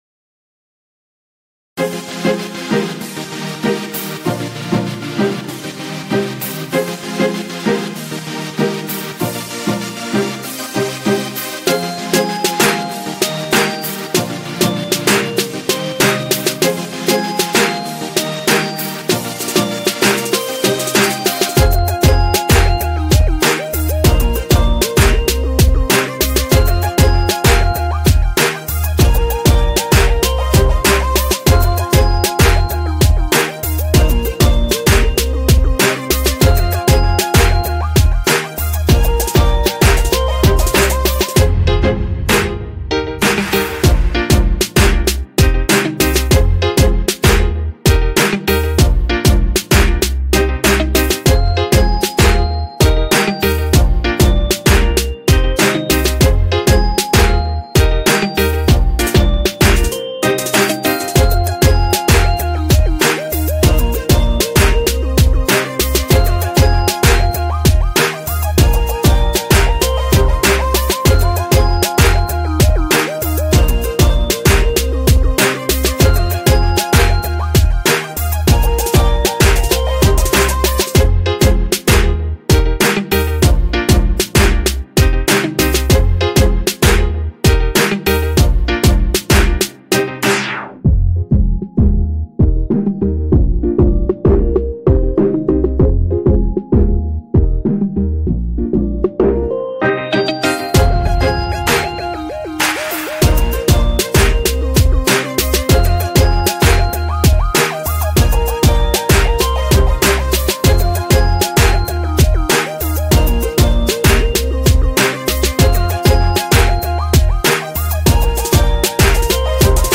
Reggae/Dancehall